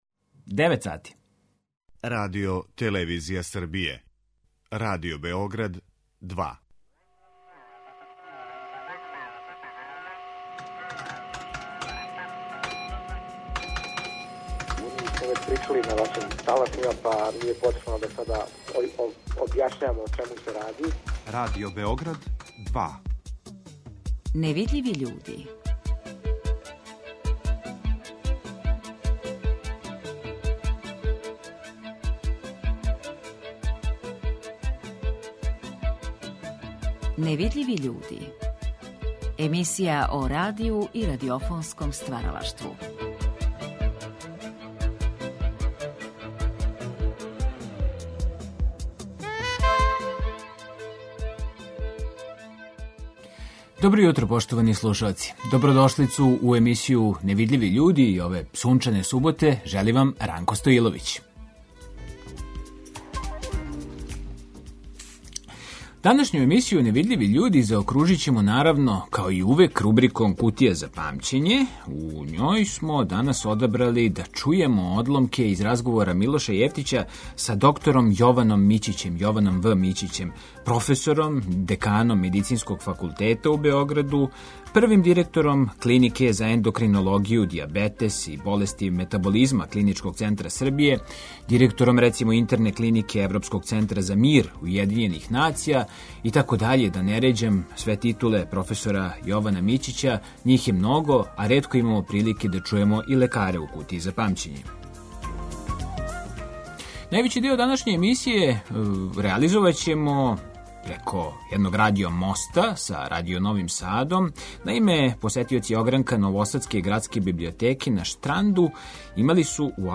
Ови разговори вођени су последње године прошлог века у циклусу емисија „Гост Другог програма".